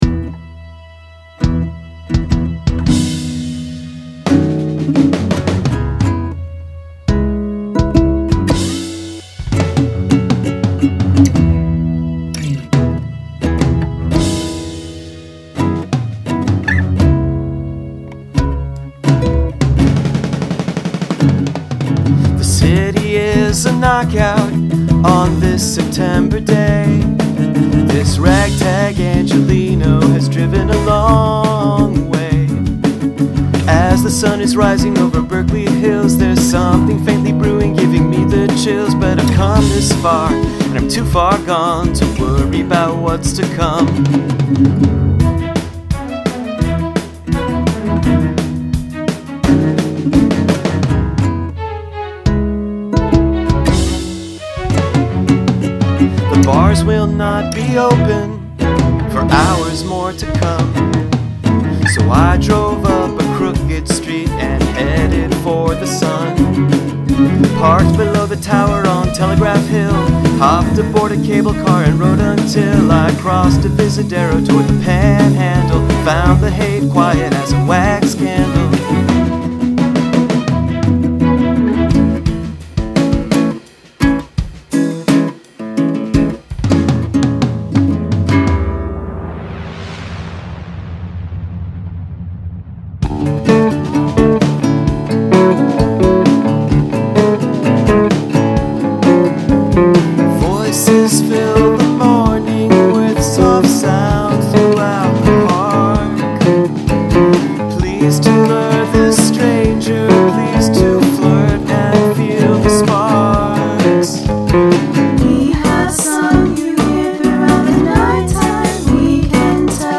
Epic story song